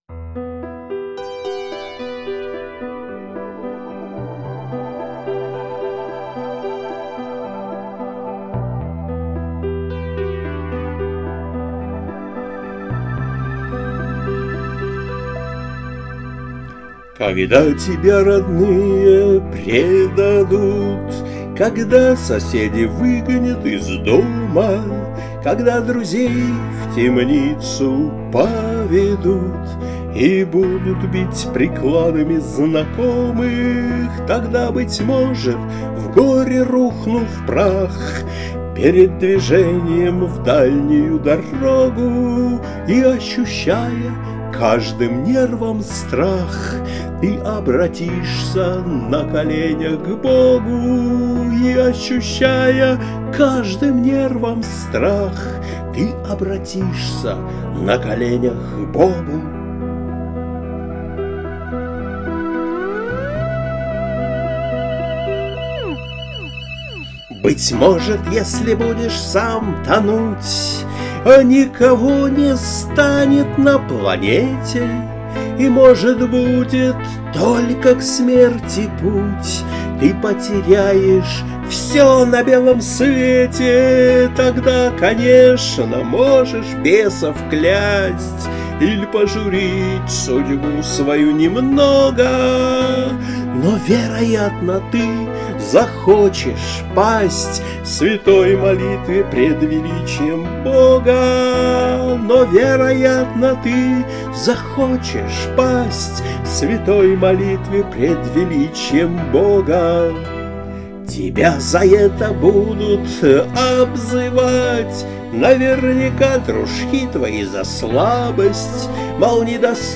Христианские музыкальные песни